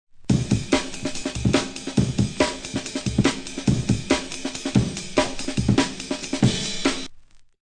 Par contre, ce n'est pas à ça que je pensais, plus un rythme de base genre poum poum tchac bien binaire mais syncopé, et en accéléré.
Ca donne un truc à peu près comme ça, en fait, avec un son beaucoup plus roots, bien sûr :
dnb.mp3